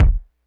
kick02.wav